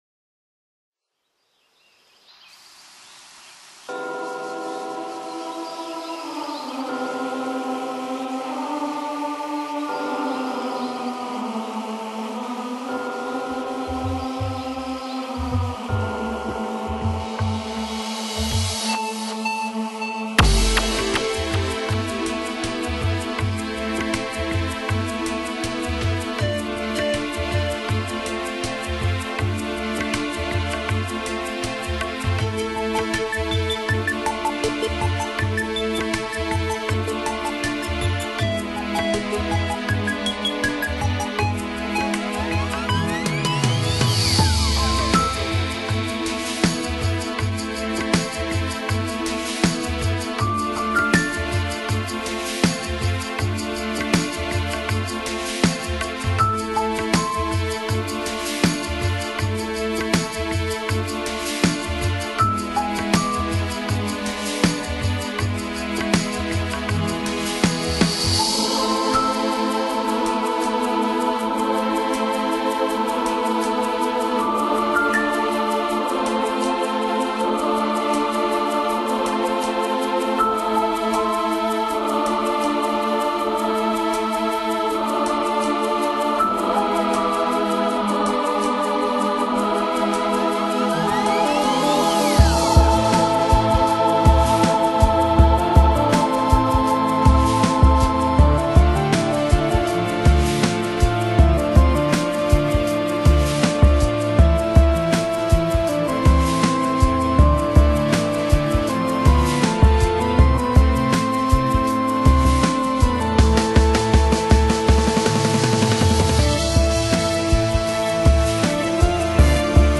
类型：Instrumental/Spiritual/Enigmatic/NewAge